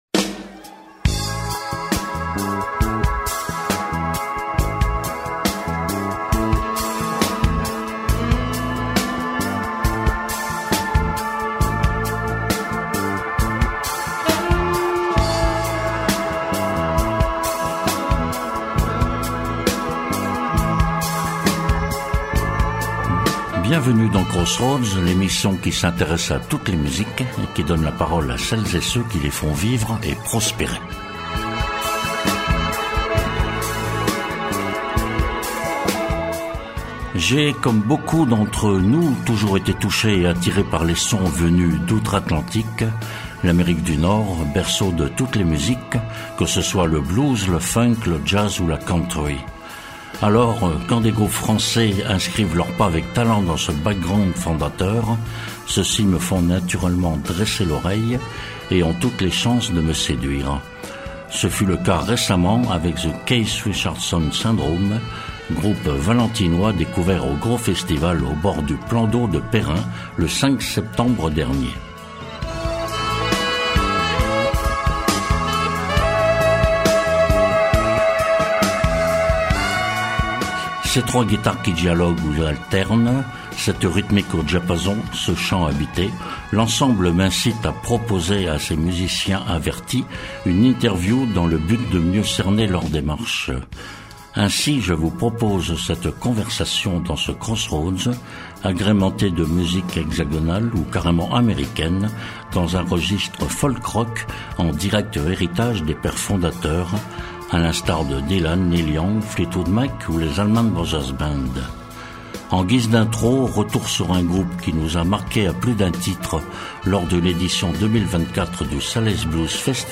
Nous allons dès lors nous promener entre rock, blues et folk en ces lieux à la fois lointains et proches de nous, tant nos oreilles ont été de tout temps bercées par ces musiques.